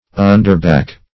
Search Result for " underback" : The Collaborative International Dictionary of English v.0.48: Underback \Un"der*back`\, n. (Brewing) A vessel which receives the wort as it flows from the mashing tub.